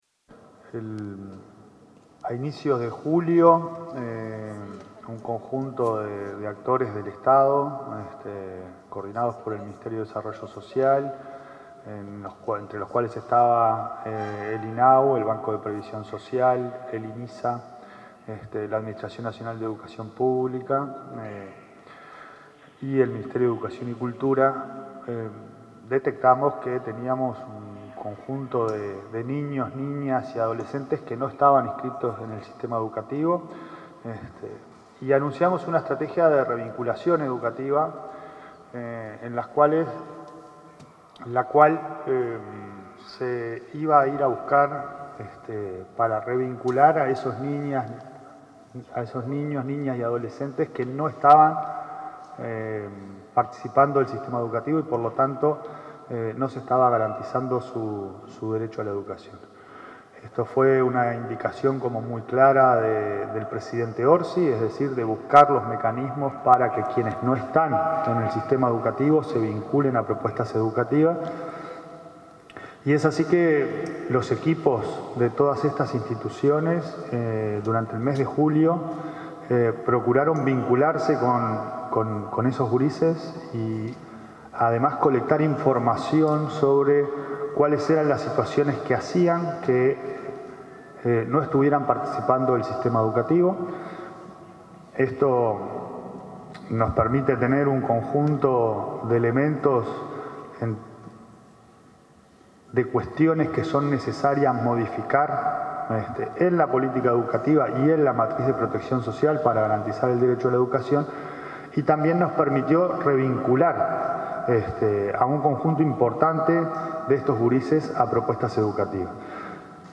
Palabras del ministro de Desarrollo Social, Gonzalo Civila, y el presidente de la ANEP, Pablo Caggiani